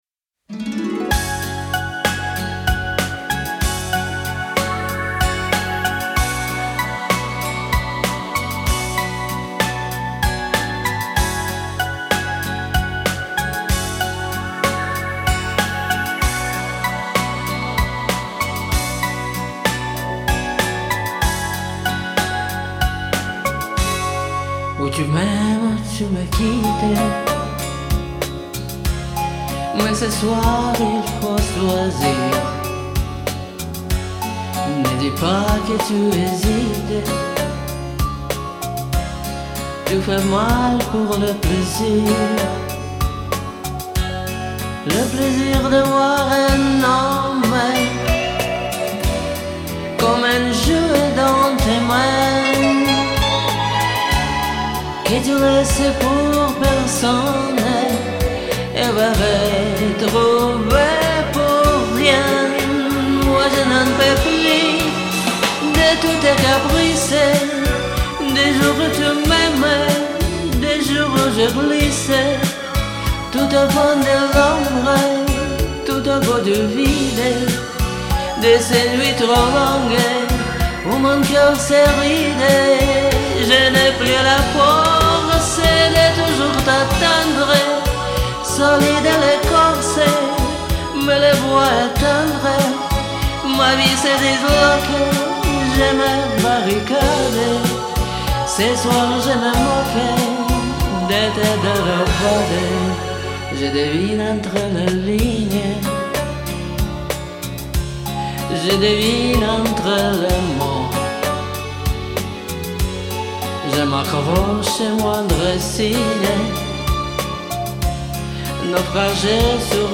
Особенно, когда поёт таким женским голосом.